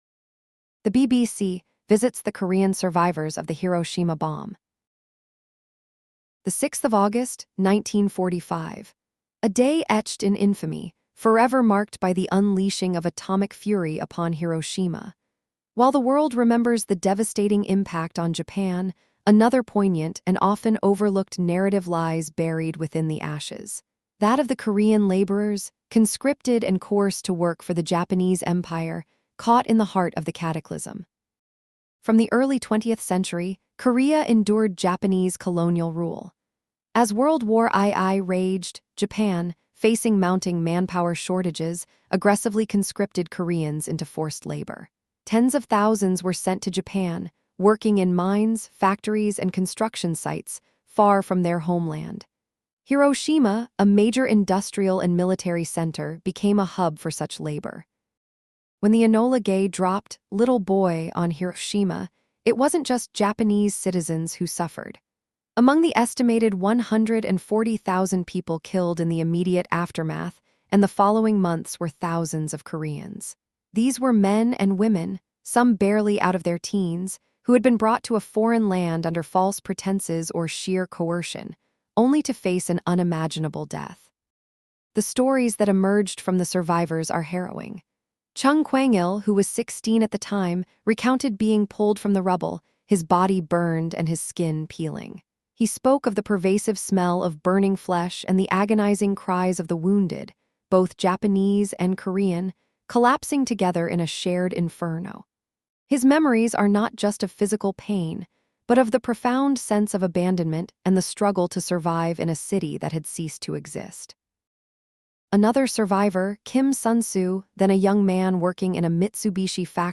The BBC Visits the Korean Survivors of the Hiroshima Bomb
In a poignant report, the experiences of Korean survivors of the Hiroshima bombing were brought to light, offering a critical and often missing dimension to the historical narrative. These interviews served not only as a testament to their resilience but also as a stark reminder of the human cost of war, transcending national boundaries.